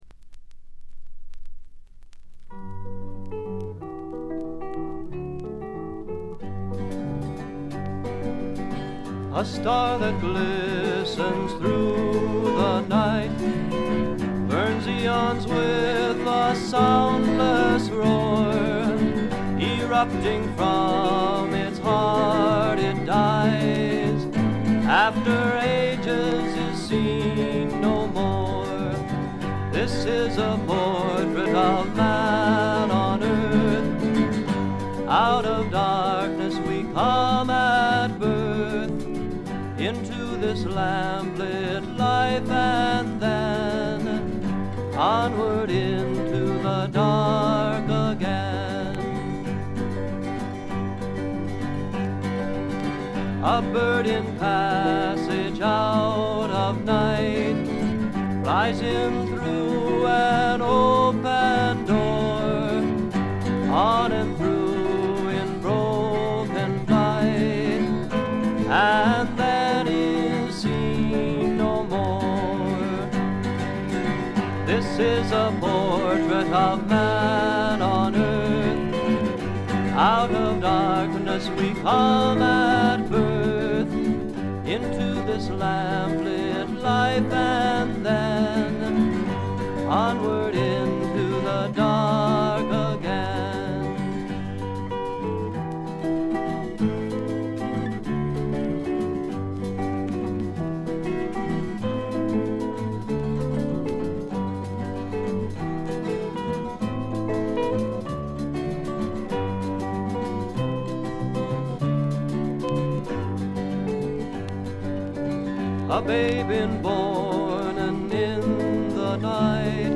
軽微なバックグラウンドノイズやチリプチ少々、プツ音2回ほど。
どういう人なのかまったく知りませんがミネアポリス録音の自主制作快作です。
試聴曲は現品からの取り込み音源です。
Vocals, Twelve-String Guitar, Kazoo
Lead Guitar
Bass Guitar
Percussion
Recorded At - United Theological Seminary